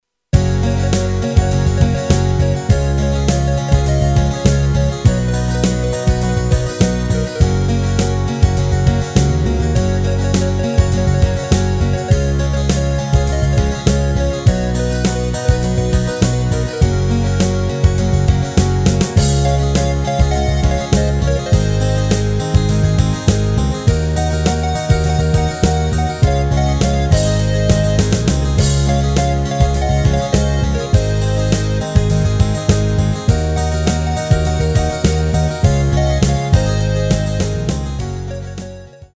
Rubrika: Pop, rock, beat